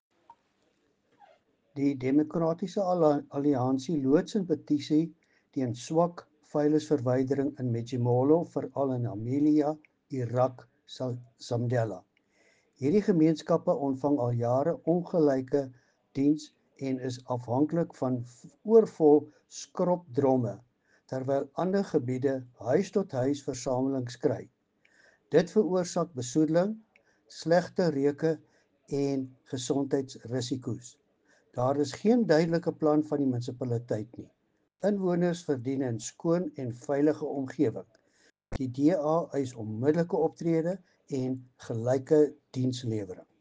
Afrikaans soundbite by Cllr Louis van Heerden.